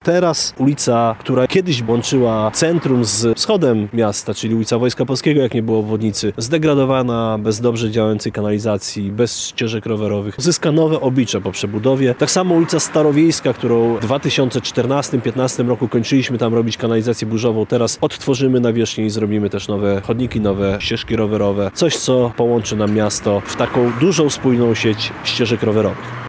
– Po Lubelskiej, Piłsudskiego i Partyzantów przyszedł czas na kolejne inwestycje, które z jednej strony służą mieszkańcom a z drugiej przyczyniają się do rozwoju gospodarczego miasta – mówi prezydent Zamościa, Andrzej Wnuk.